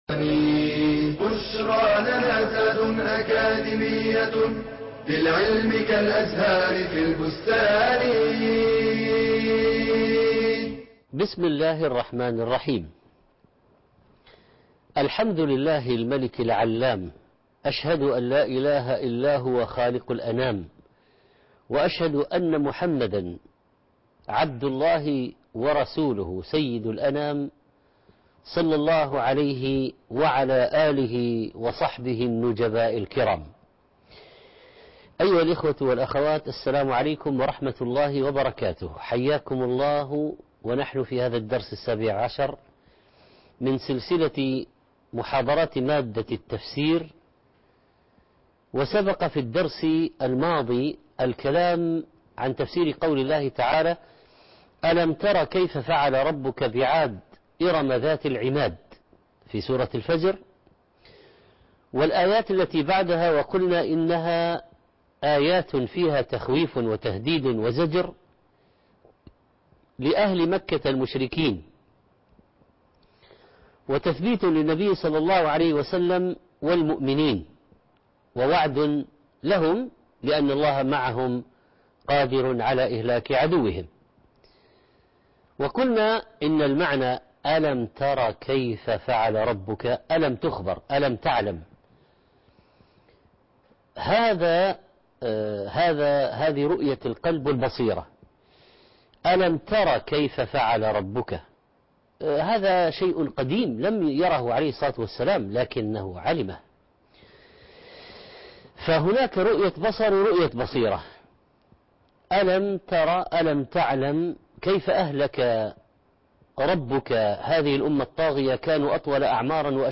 المحاضرة السابع عشر - سورة الفجر ( 1/4/2017 ) التفسير - الشيخ محمد صالح المنجد